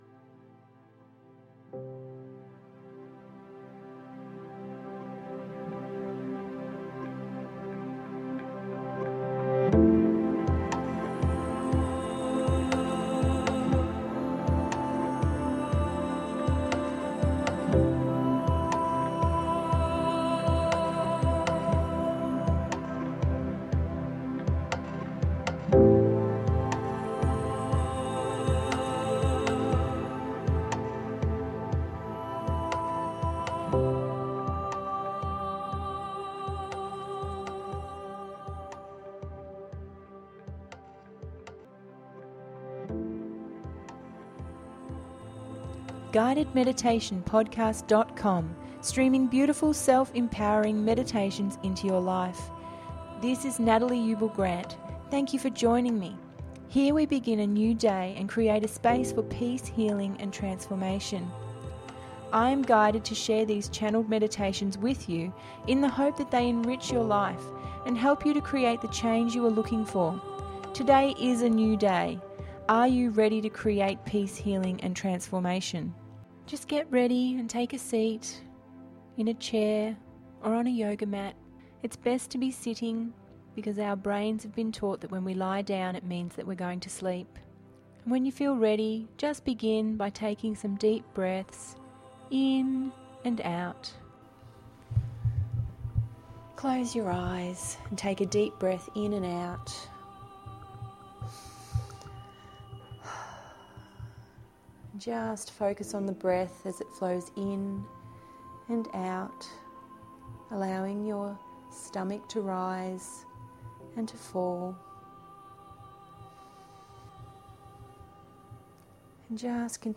With a gentile guiding voice you can let the mind chatter be and become an observer of thought.
Lotus flower heart space meditation is a gentile relaxing meditation that takes you on a journey within creating the connection to the universal energy.